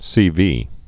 (sēvē)